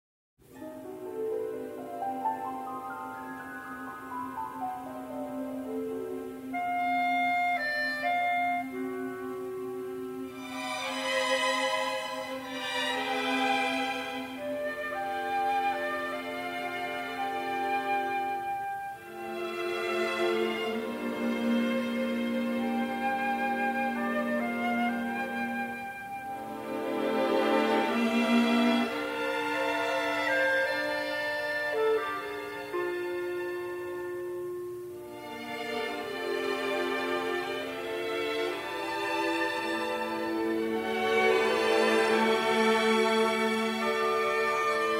trumpet
was only issued in mono in 1958